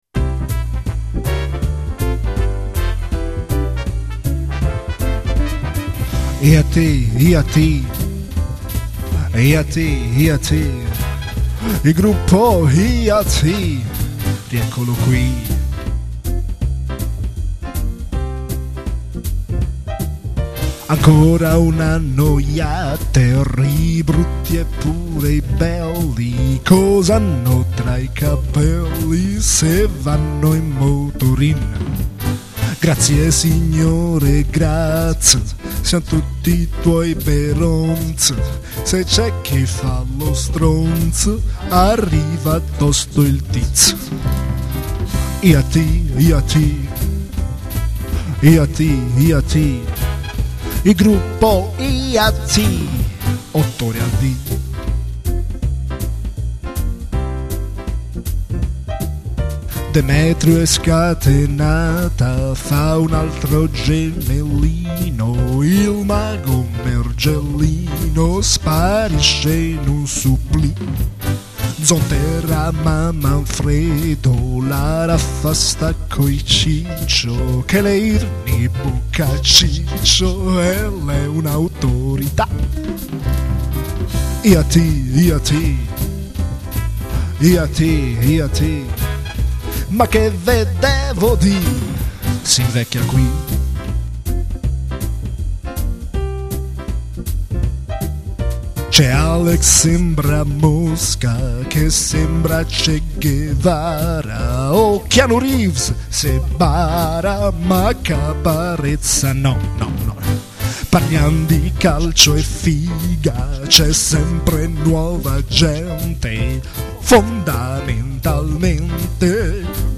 stavolta in versione jazzata...